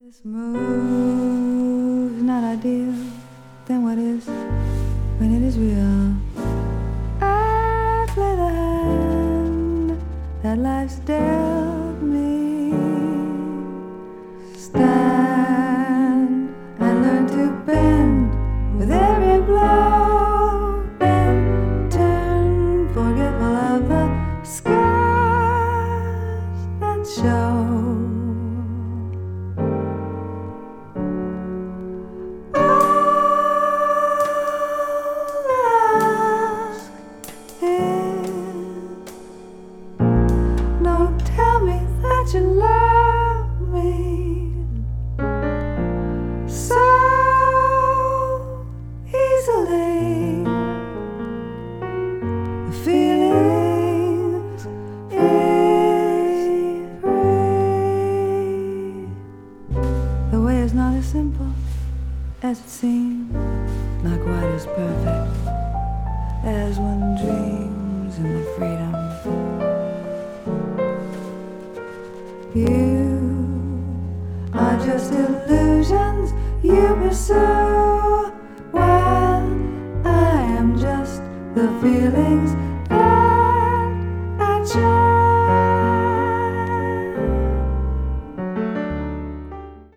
avant-garde   avant-jazz   contemporary jazz   jazz vocal